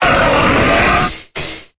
Cri d'Hippodocus dans Pokémon Épée et Bouclier.